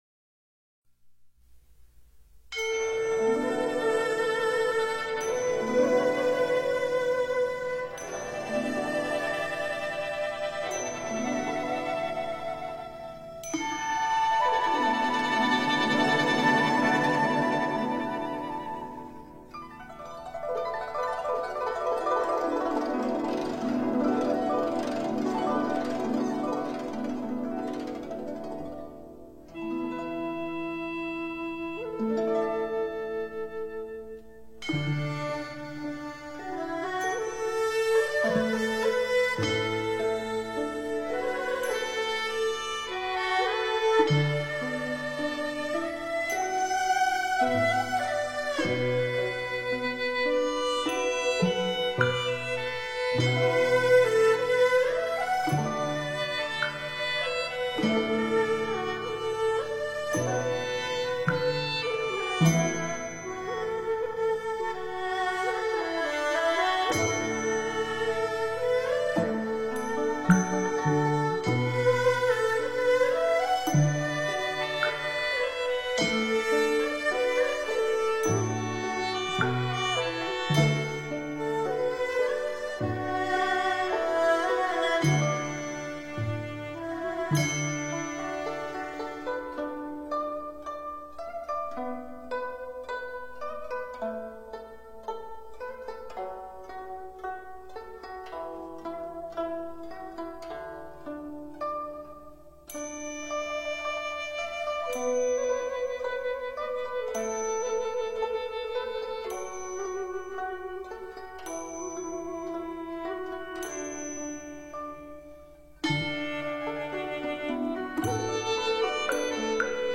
佛音 诵经 佛教音乐 返回列表 上一篇： 观.自在(金刚手菩萨心咒